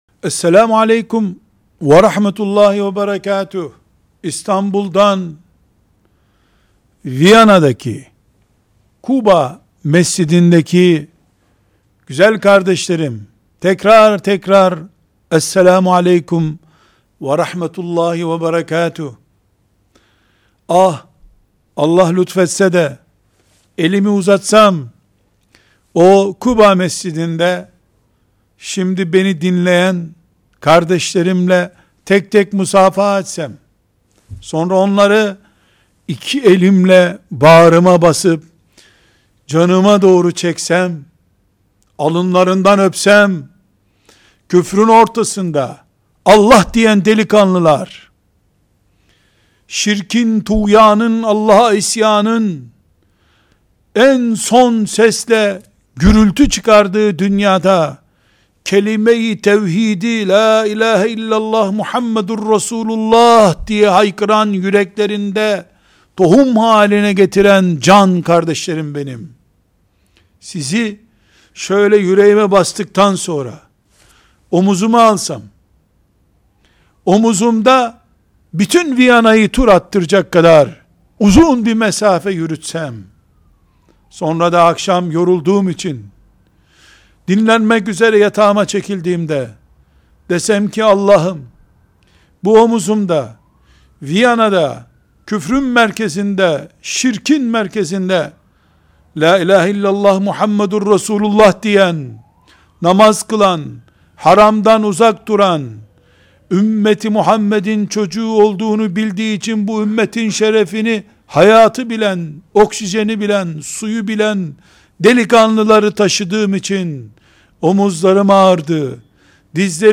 289) Peygamberimin ümmetiyim diyen gençler! – Avusturya – Viyana/Telekonferans
2. Konferanslar